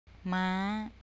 HIGH
MAAH HORSE